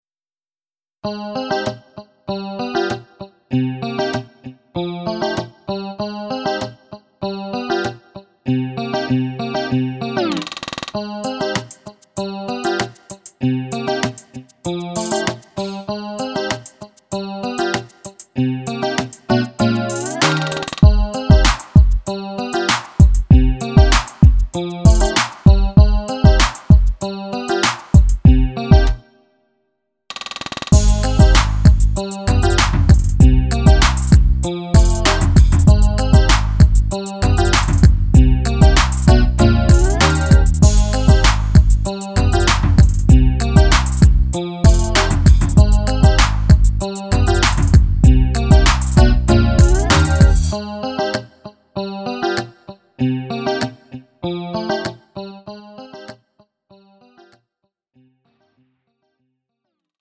음정 원키 2:52
장르 가요 구분 Lite MR